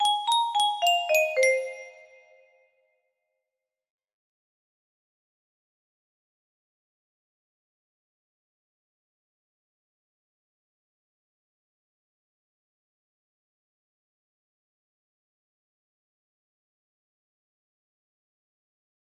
another thing music box melody
Full range 60